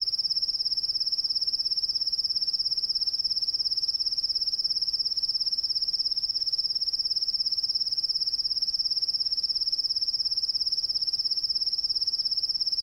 main Divergent / mods / Soundscape Overhaul / gamedata / sounds / ambient / soundscape / insects / insectday_5.ogg 300 KiB (Stored with Git LFS) Raw Permalink History
insectday_5.ogg